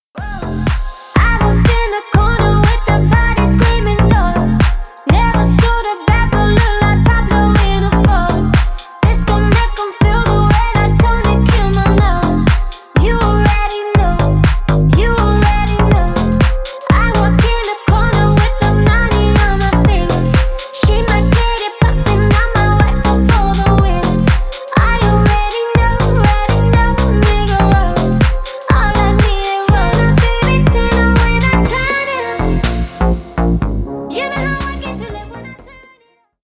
Ремикс # Танцевальные
клубные